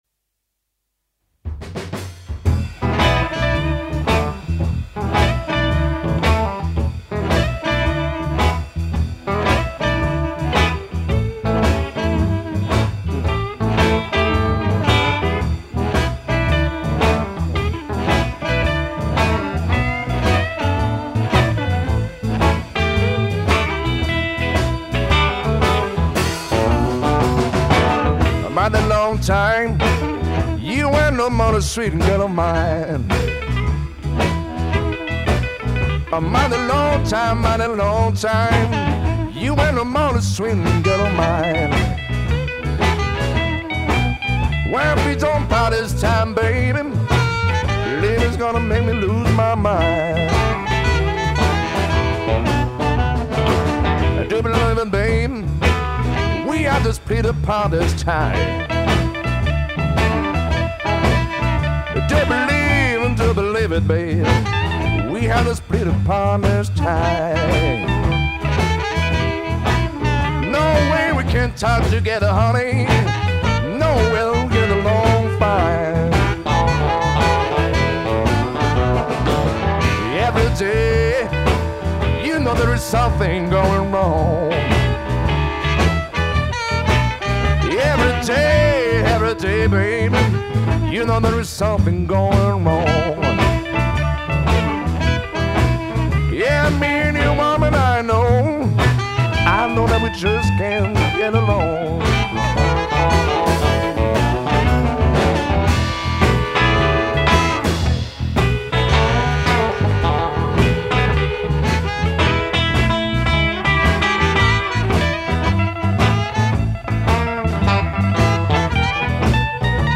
la voix profonde
chant, harmonica, guitare
basse
batterie